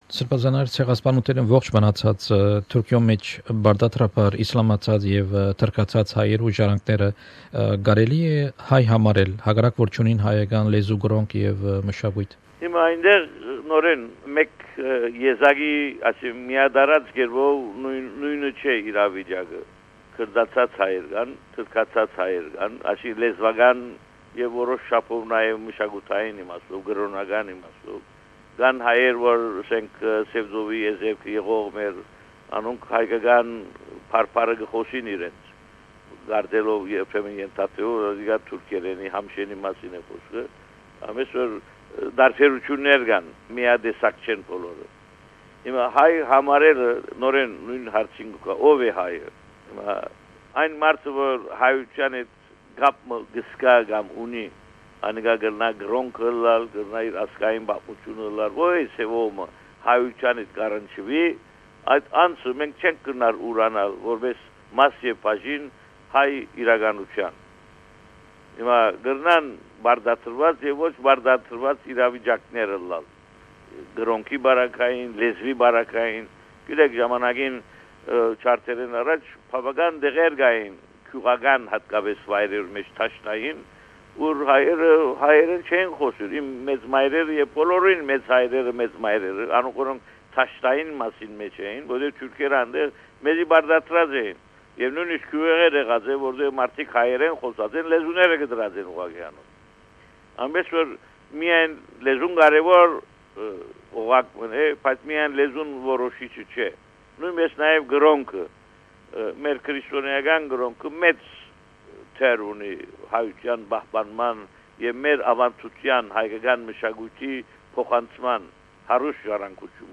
Interview with Bishop Haygazoun Najarian, Primate of Armenians in Australia & New Zealand on Armenian identity.